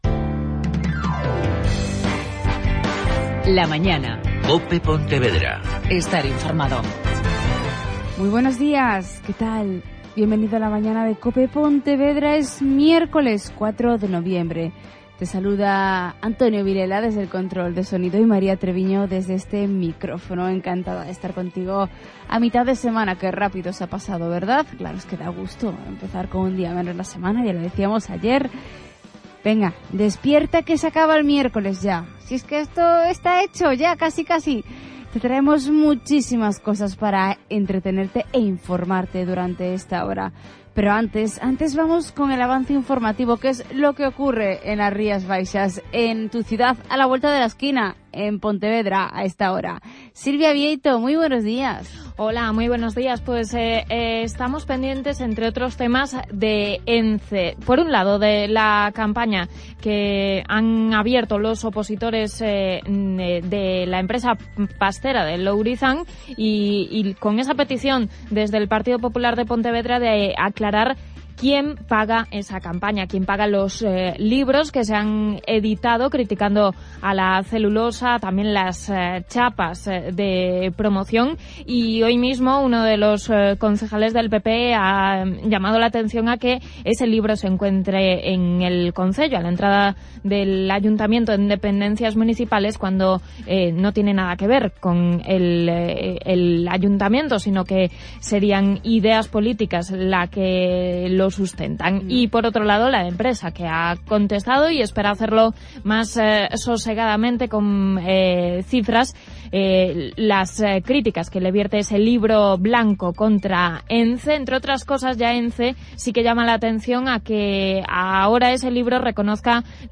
Mi intervención empieza en el minuto 20:30: